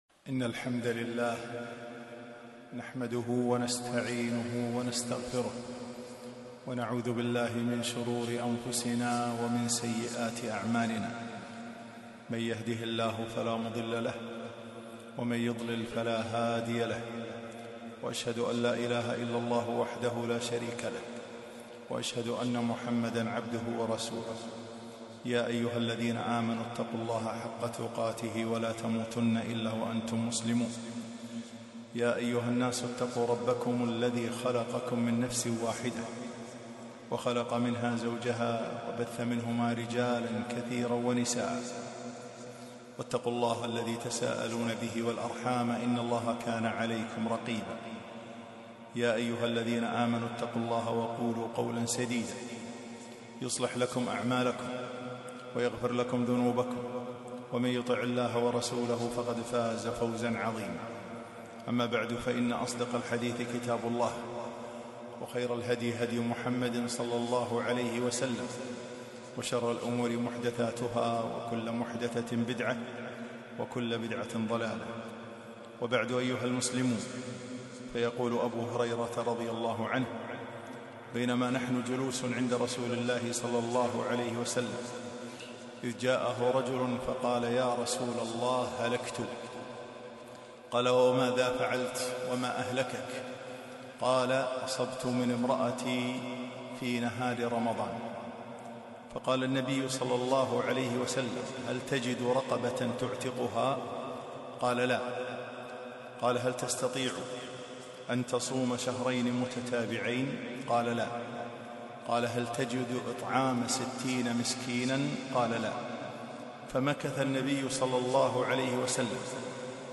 خطبة - السنة النبوية... آداب وأحكام